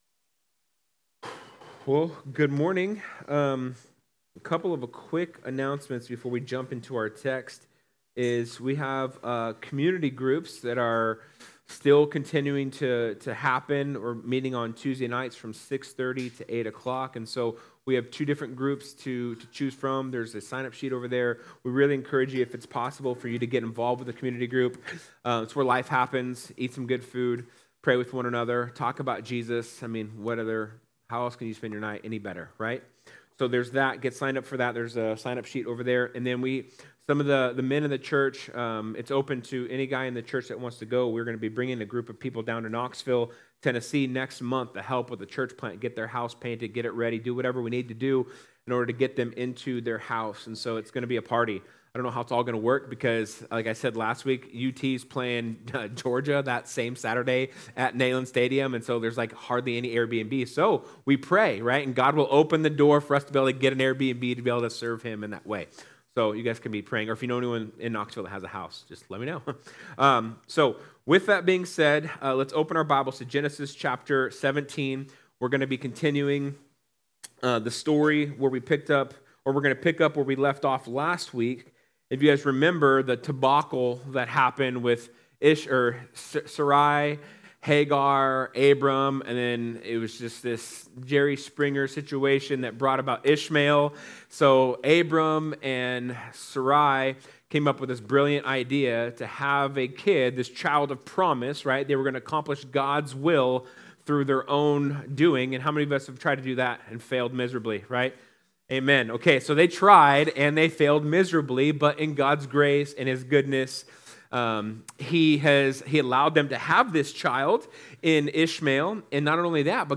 Sermons | The Table Fellowship